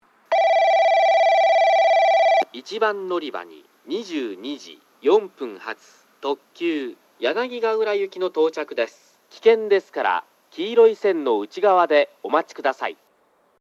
1番のりば接近放送　男声
スピーカーはJVCラインアレイですが、設置数が多いので収録が行いやすくなっています。